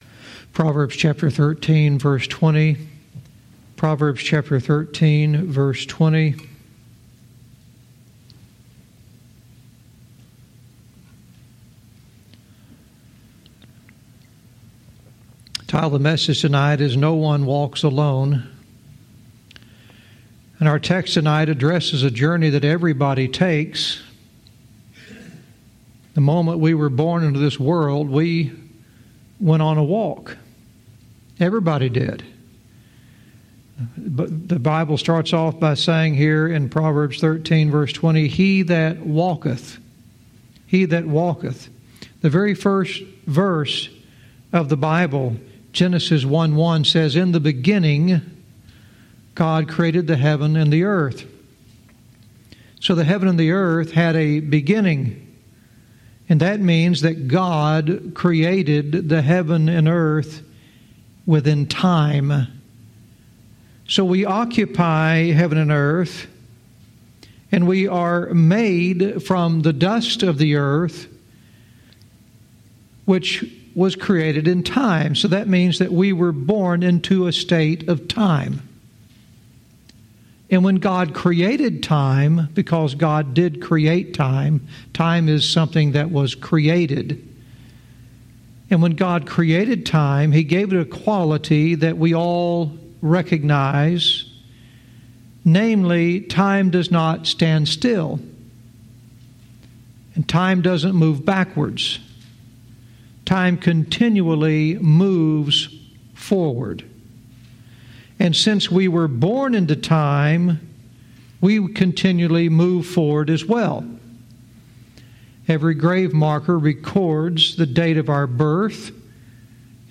Verse by verse teaching - Proverbs 13:20 "No One Walks Alone"